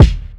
He's Back Kick.wav